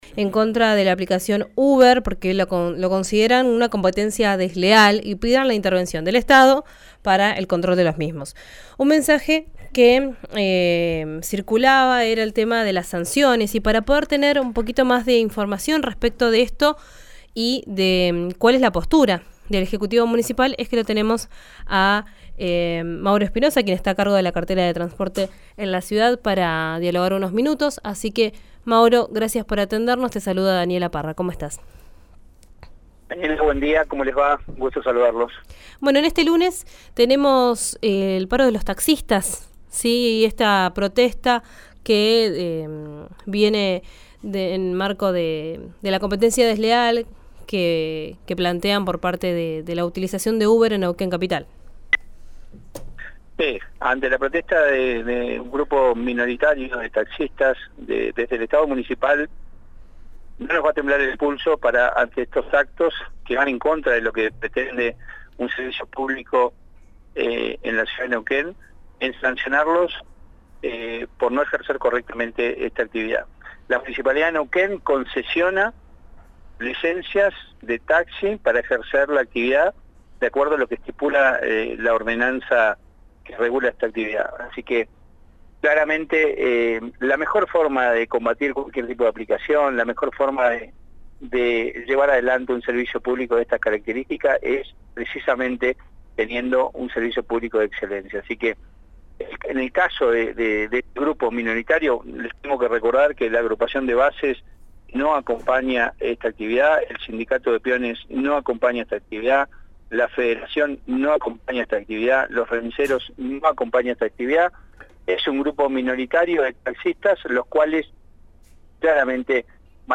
Escuchá al subsecretario de transporte, Mauro Espinosa, en RÍO NEGRO RADIO